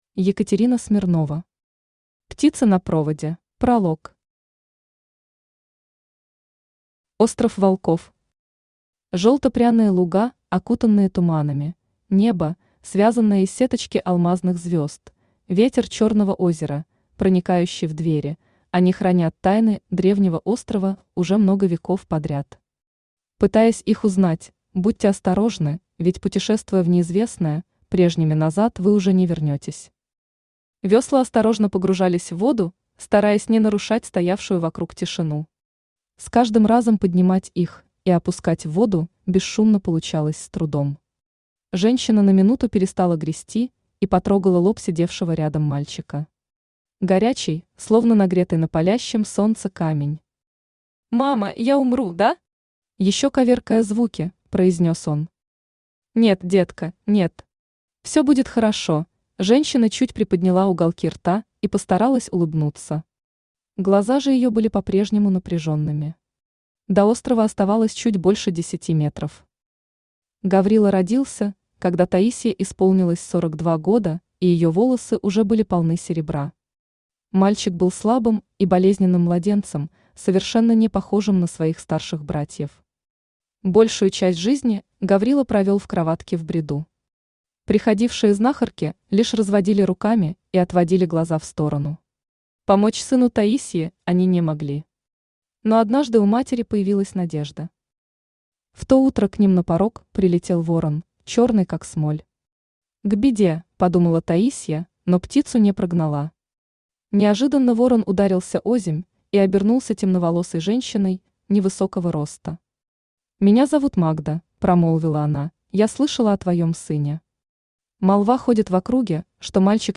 Аудиокнига Птицы на проводе | Библиотека аудиокниг
Aудиокнига Птицы на проводе Автор Екатерина Смирнова Читает аудиокнигу Авточтец ЛитРес.